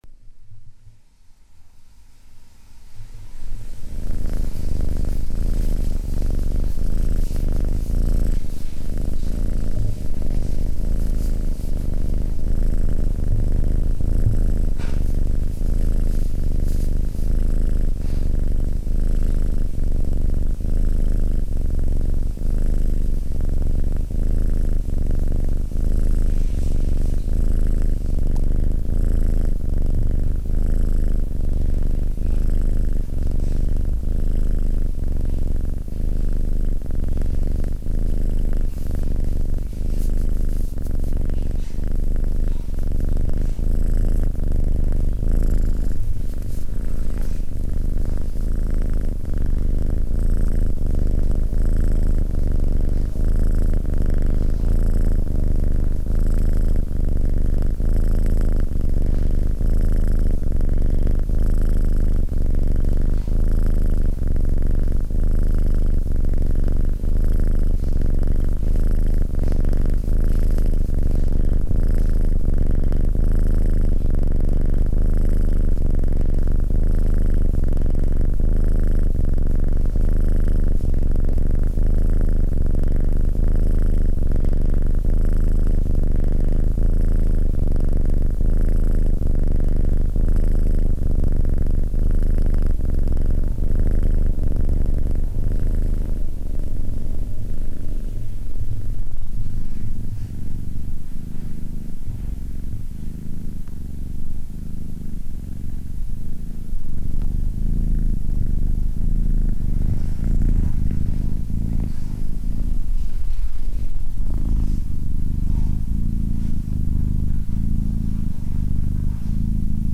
Звуки мурчания кошек
Долгое умиротворяющее мурлыканье кошки